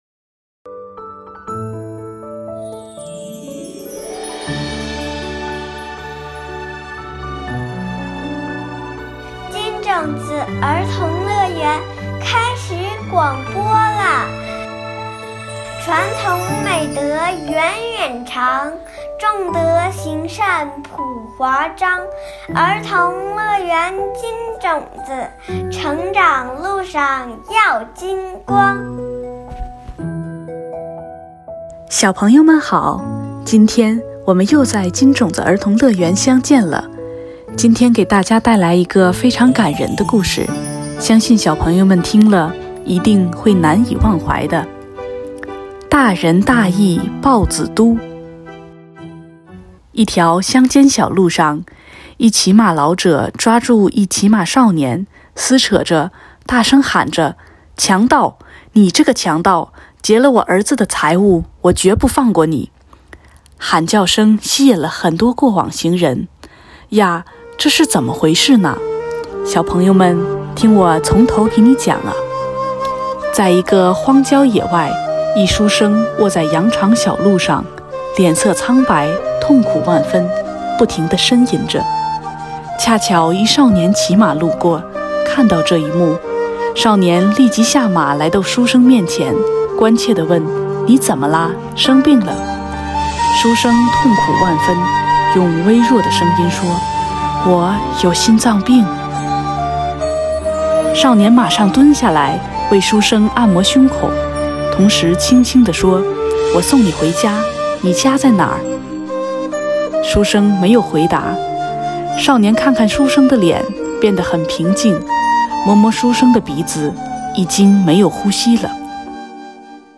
金種子兒童樂園系列廣播故事（音頻）： 第六期《大仁大義鮑子都》